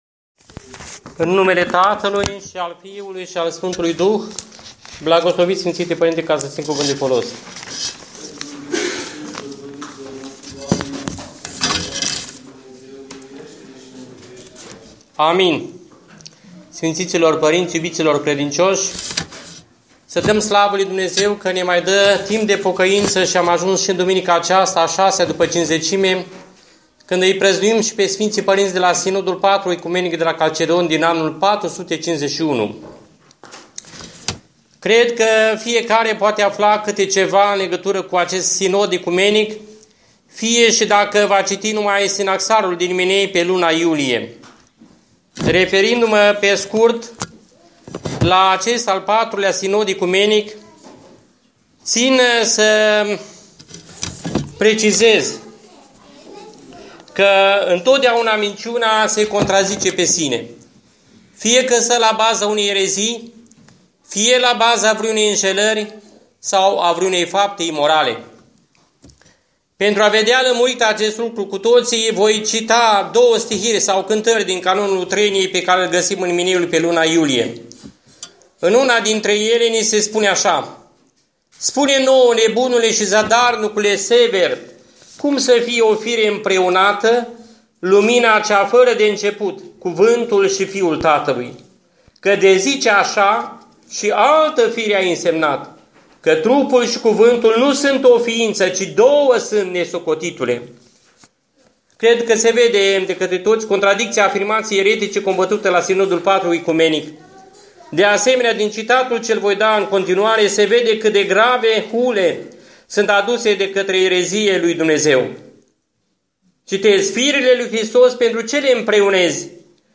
Predici